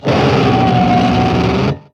Cri de Gigalithe dans Pokémon X et Y.